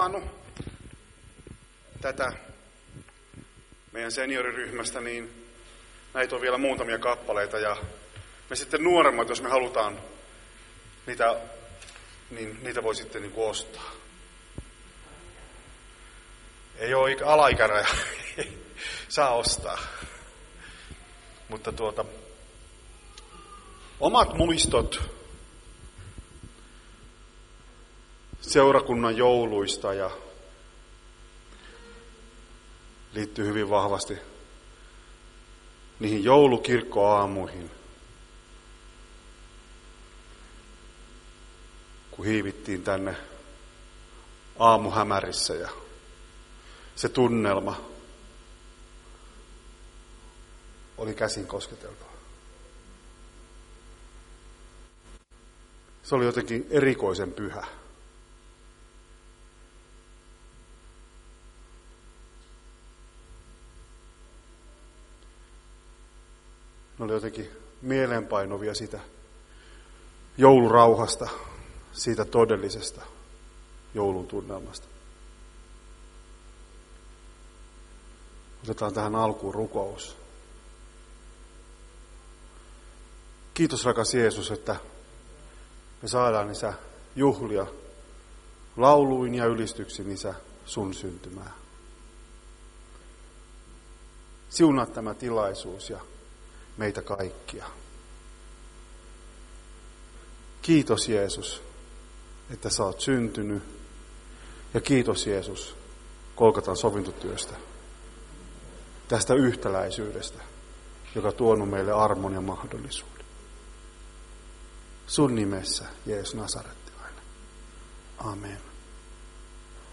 Joululaulujen ilta 22.12.2024